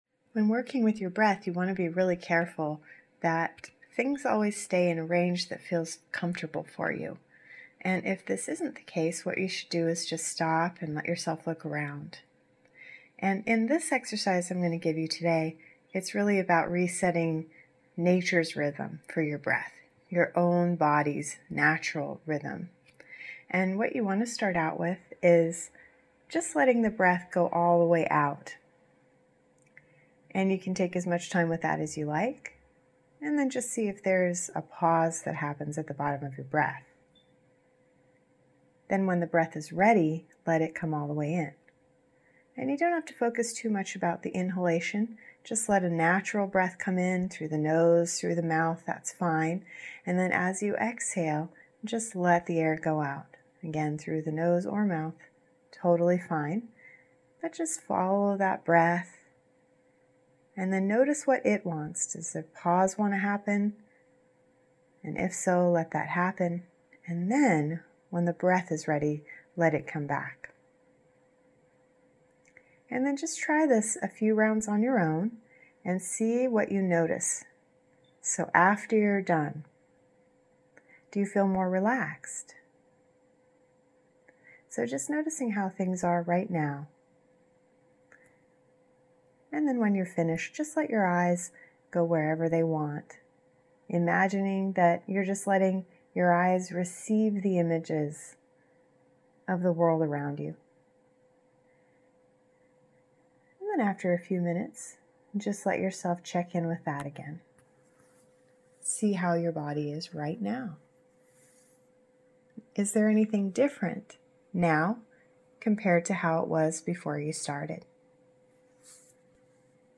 Breath-Reset.mp3